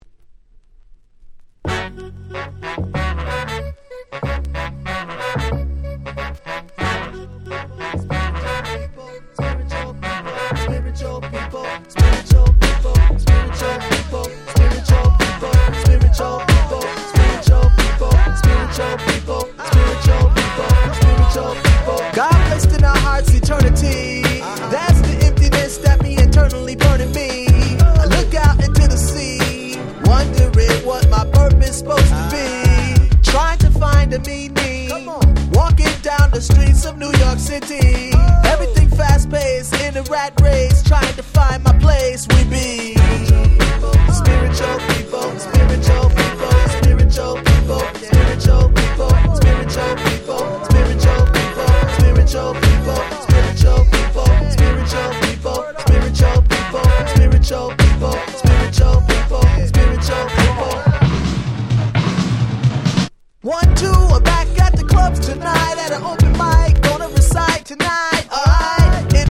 00' Very Nice Hip Hop / R&B !!
Boom Bap